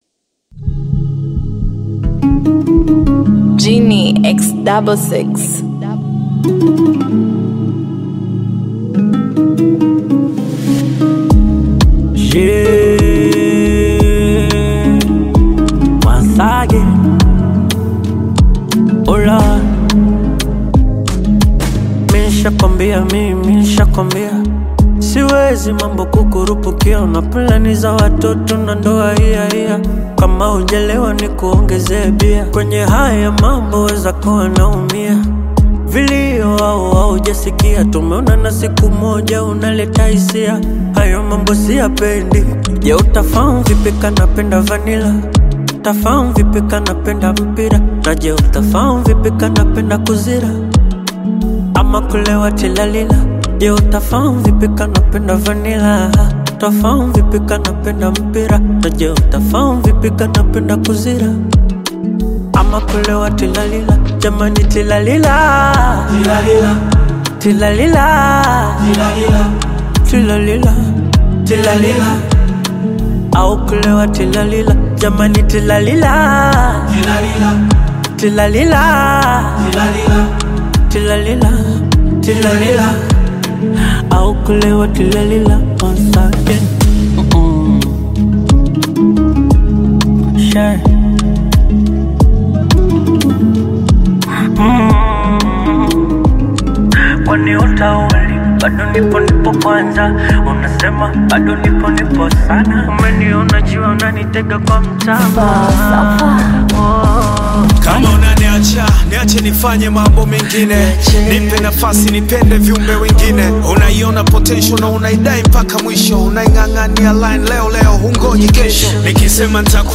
a soulful Bongo Flava collaboration
Genre: Bongo Flava